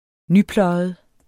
Udtale [ ˈnyˌplʌˀjəð ]